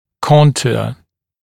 [‘kɔntuə][‘контуэ]форма, контур